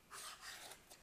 cut & peel a pumpkin
Sound Effect
Sounds of cutting and peeling a special sort of pumpkin.
peel_pumpkin_02.ogg